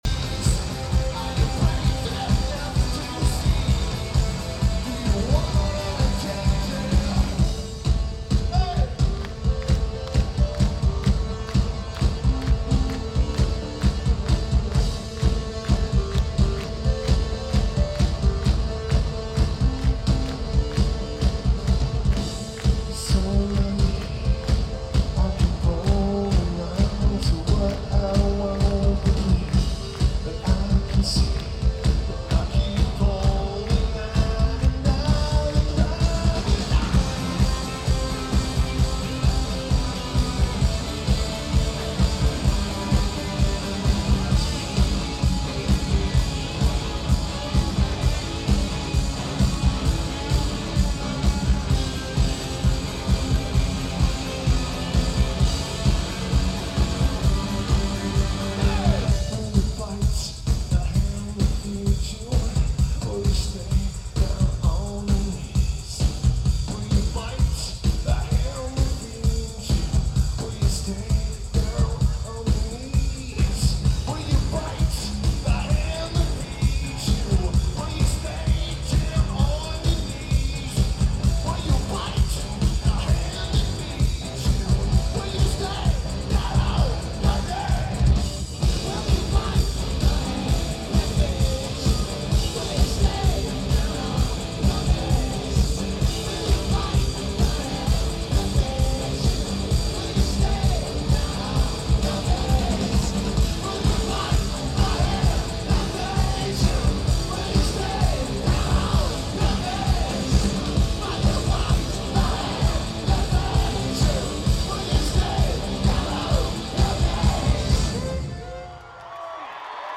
New England Dodge Music Center
Lineage: Audio - AUD (AT853 + SP-SPSB-3 + iRiver H120)